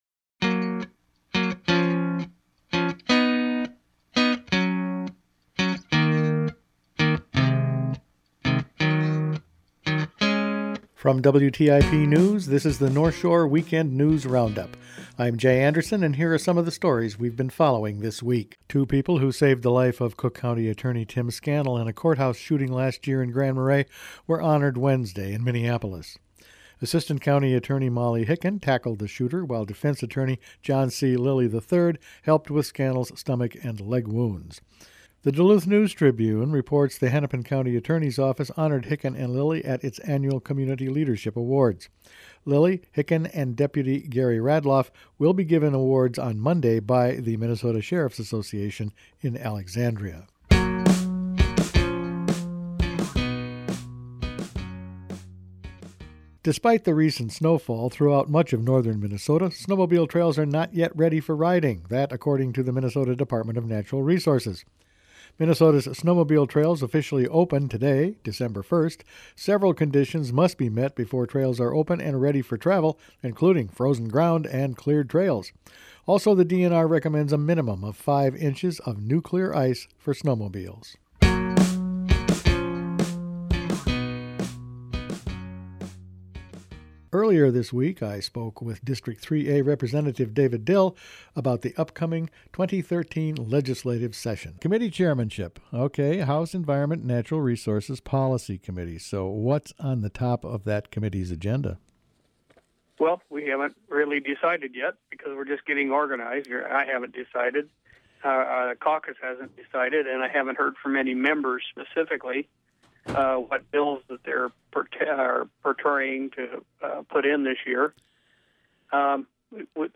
Attachment Size WrapFinalCut_113012.mp3 18.49 MB Each weekend WTIP news produces a round up of the news stories they’ve been following this week. An impaired waters study from MPCA, a conversation with Rep. David Dill, not ready for snowmobiles snowmobile trails and more…all in this week’s news.